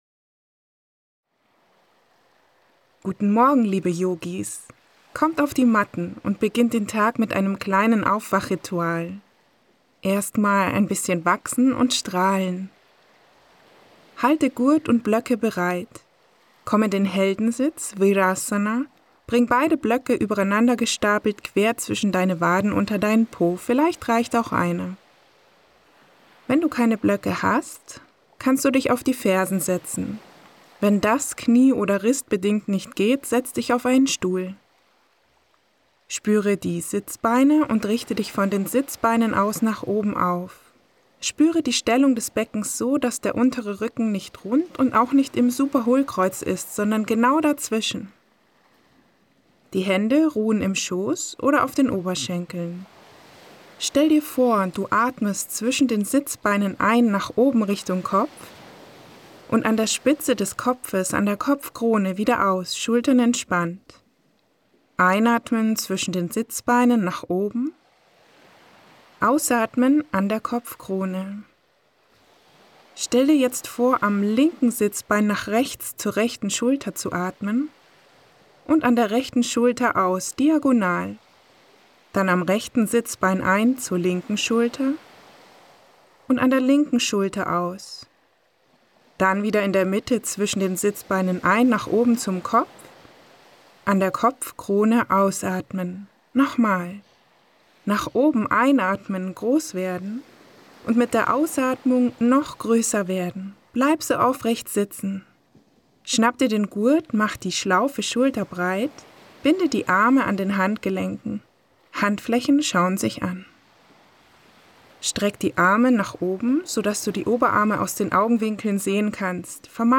Vinyasa Yoga Level 2: Carpe Diem Royale.